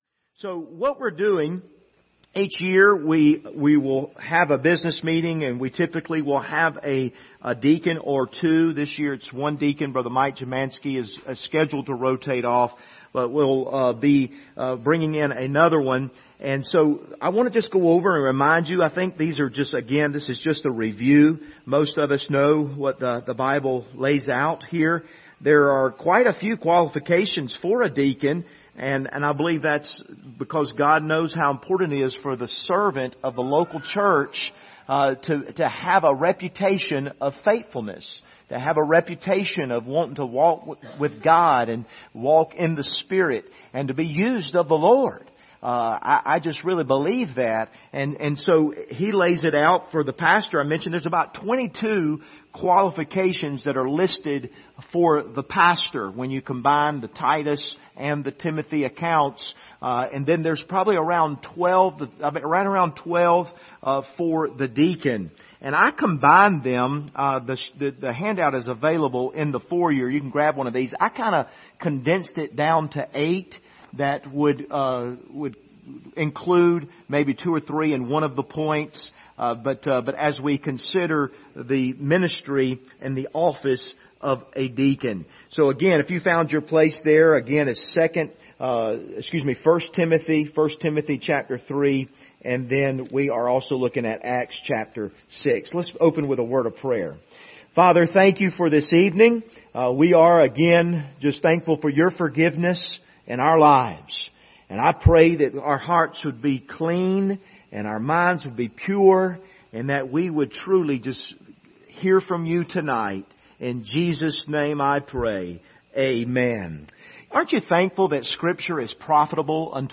Passage: 1 Timothy 3:8-10, Acts 6:1-8 Service Type: Sunday Evening